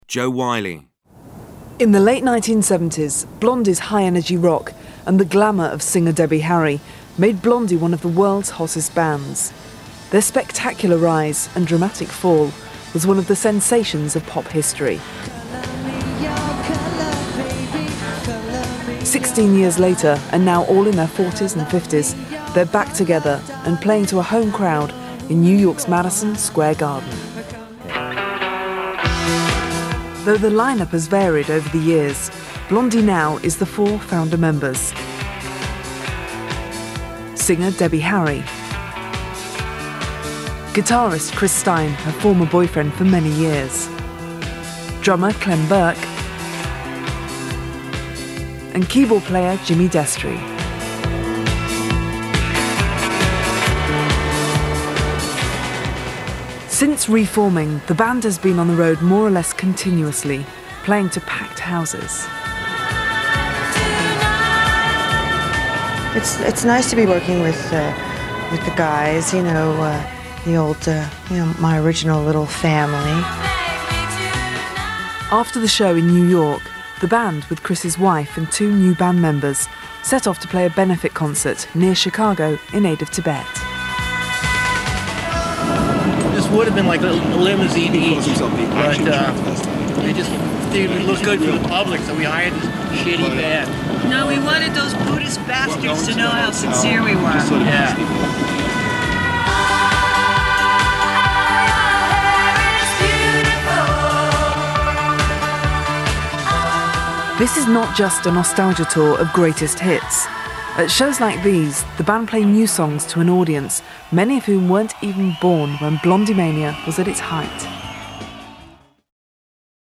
Jo has a distinctively deep and intelligent tone to her voice and is instantly recognisable.  She has a conversational soft sell, is great for musical promos and gives your commercial reads a classy edge.
• Female
• London
• Standard English R P
Jo Whiley – Narration/Documentary clip.
Jo-Whiley-narrative-doc.mp3